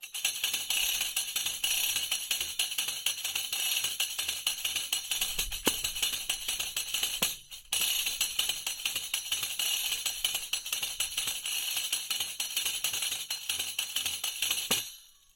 pandeireta.mp3